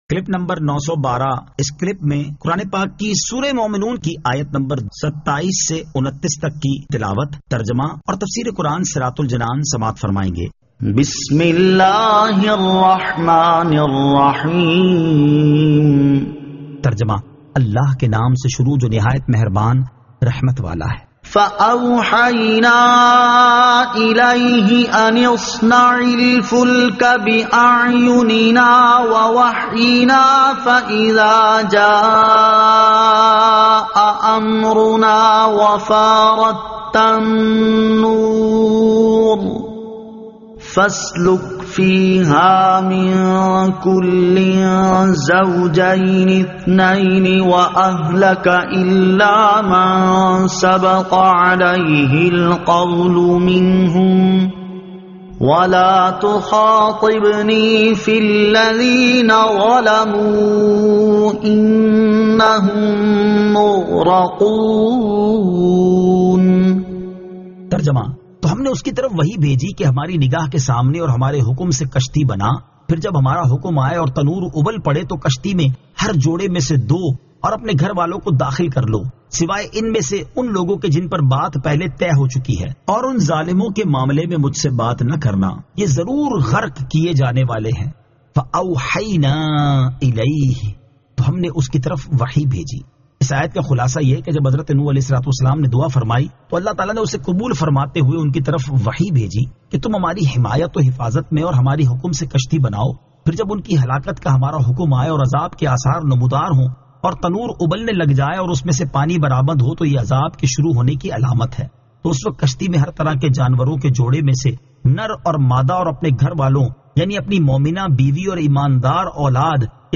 Surah Al-Mu'minun 27 To 29 Tilawat , Tarjama , Tafseer